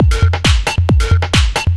DS 135-BPM A4.wav